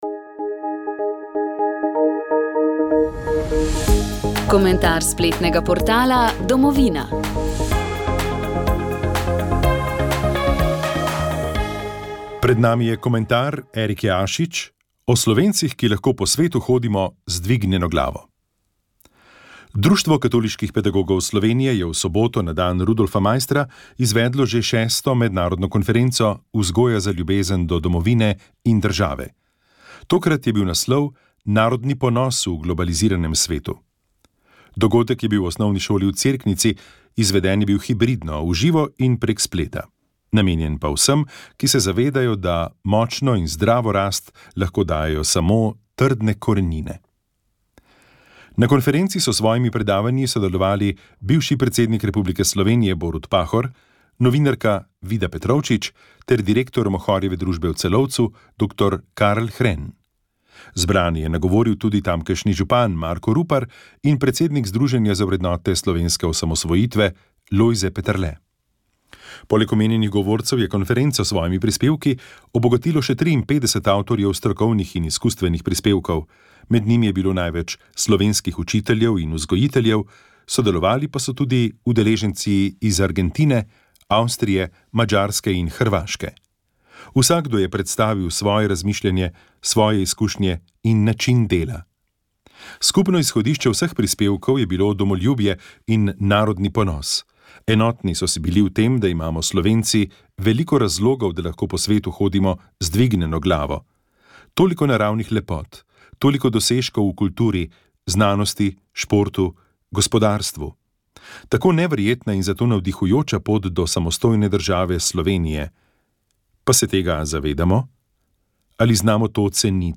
S predstavniki lokalne skupnosti, civilne iniciative in policije smo spregovorili o reševanju romske problematike na jugovzhodu države. Dotaknili smo se trenutnih varnostnih razmer, ukrepov v sklopu Šutarjevega zakona, dodatno predlaganih rešitev na področju socialne in delovne aktivacije ter pričakovanj različnih akterjev.